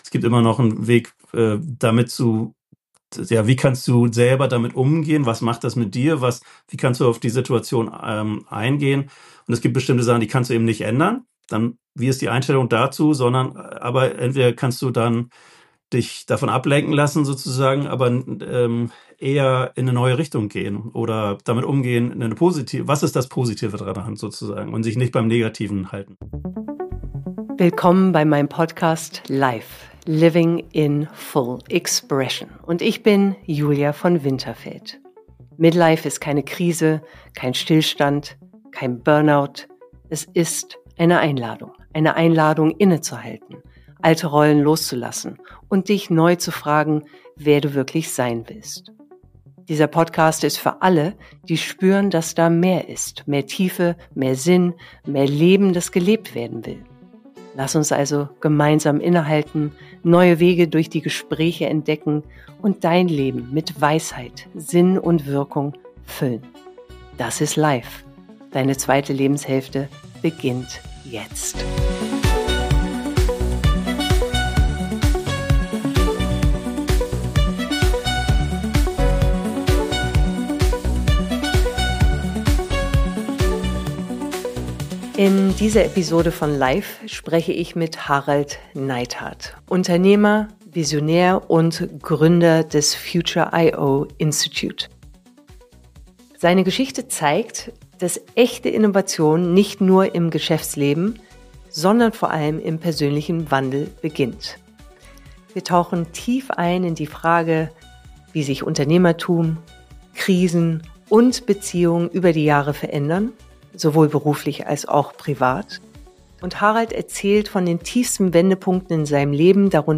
Ein Gespräch über Neuanfänge, persönliche Krisen, unternehmerische Brüche – und darüber, warum es sich lohnt, neugierig zu bleiben.